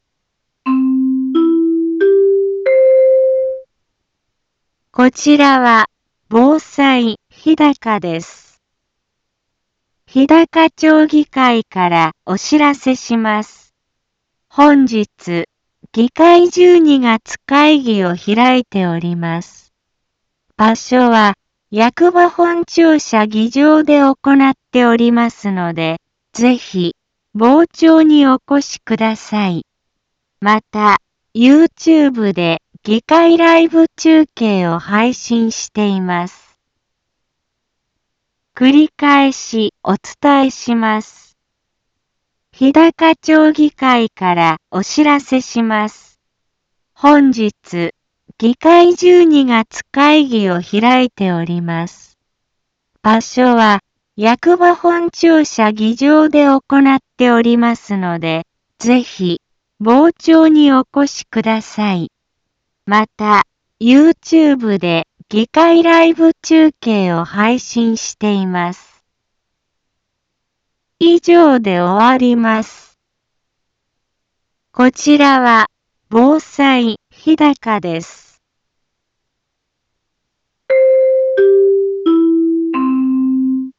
Back Home 一般放送情報 音声放送 再生 一般放送情報 登録日時：2024-12-11 10:02:58 タイトル：日高町議会12月会議のお知らせ インフォメーション： こちらは、防災日高です。